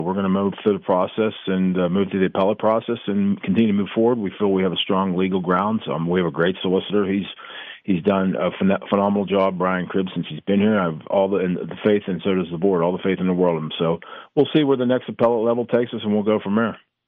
In an interview on Indiana in the Morning today